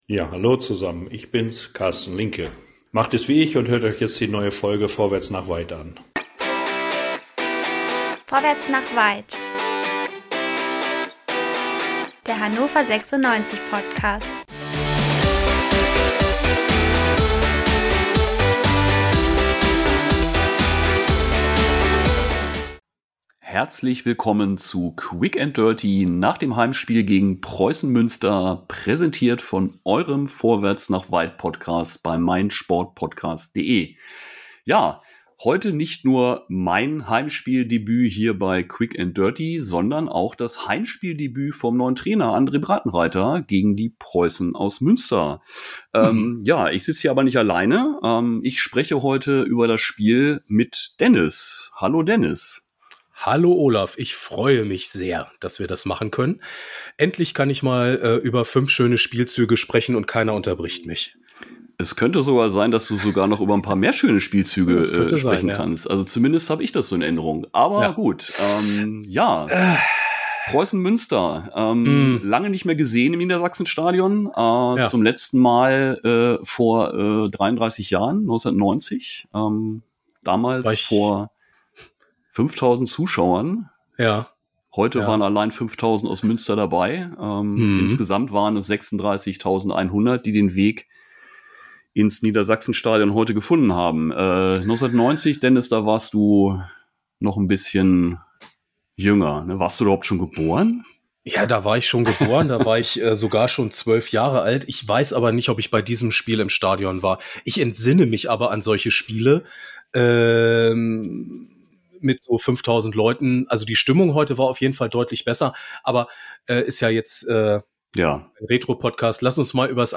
Fantalk